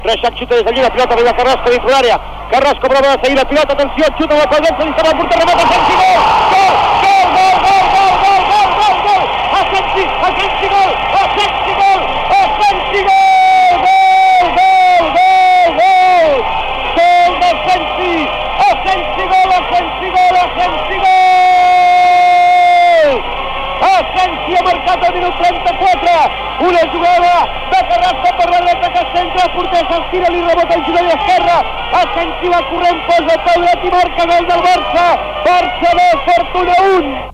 Transmissió de la final de la Recopa d' Europa de futbol entre el FC Barcelona i el Fortuna de Düsseldorf al Saint Jakob Stadium, de Basilea (Suïssa).
Esportiu